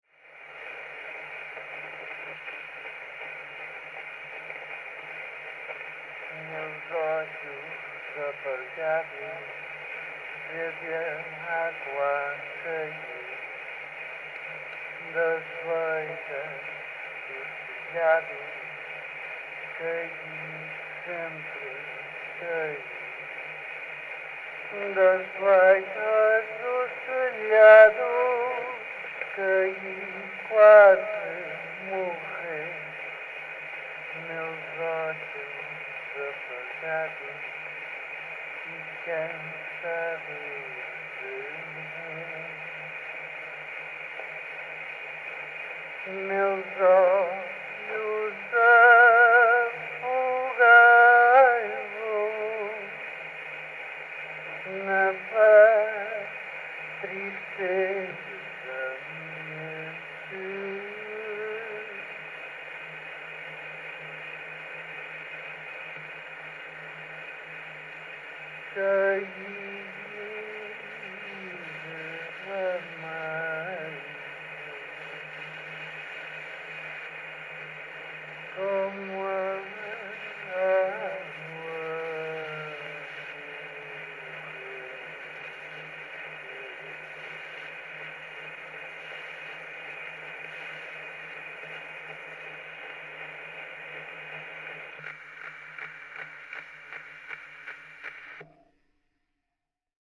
Original sound from the phonographic cylinder.